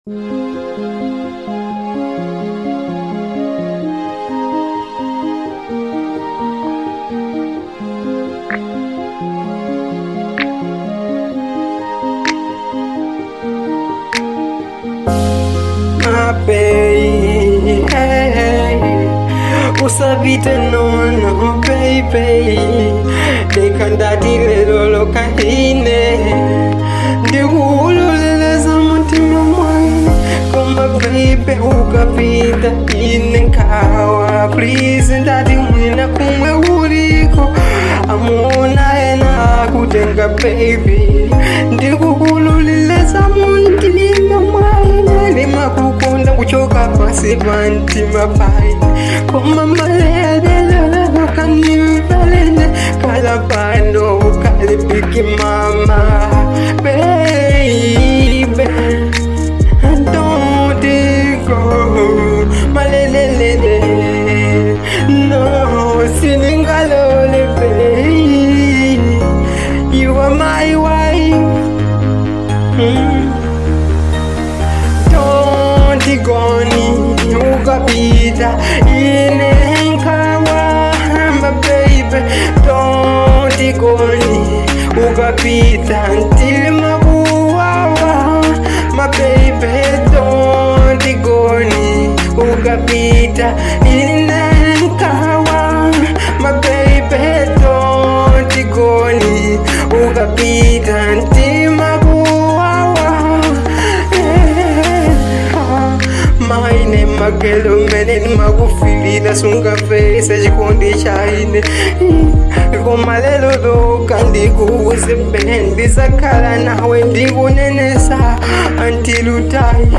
Genre : Afro soul